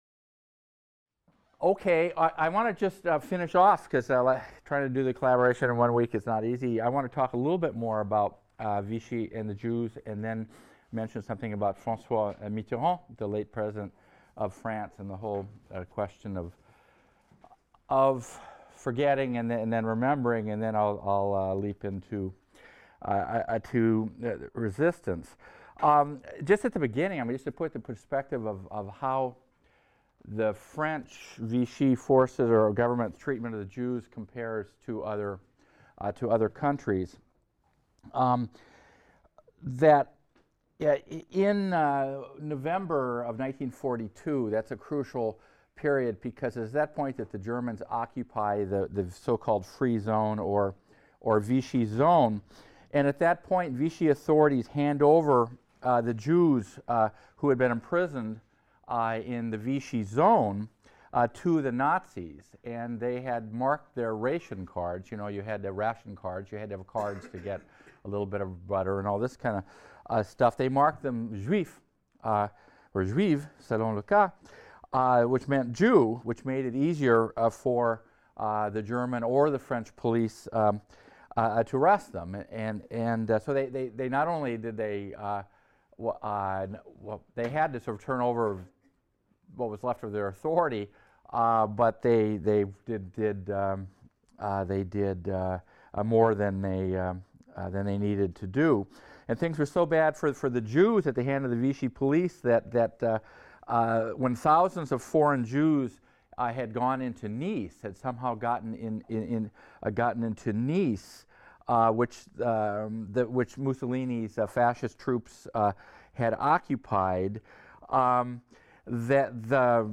HIST 276 - Lecture 19 - Resistance | Open Yale Courses